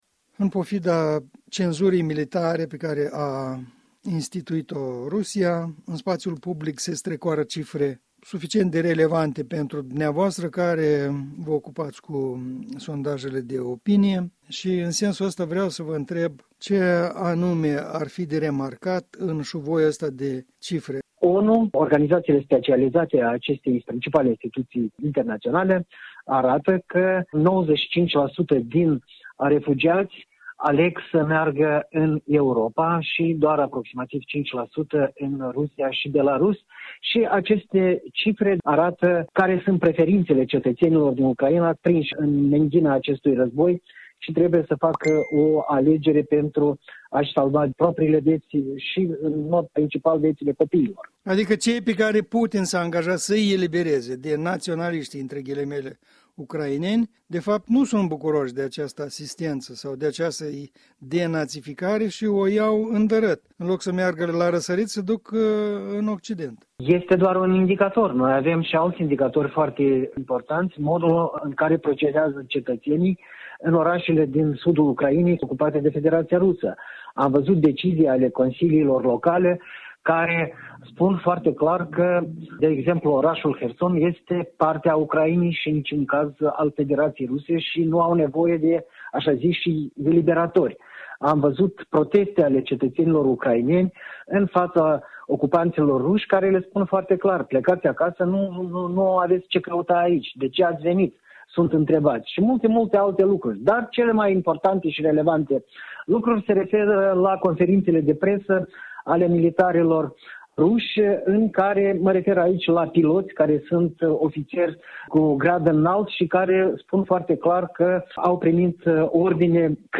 în dialog cu analistul politic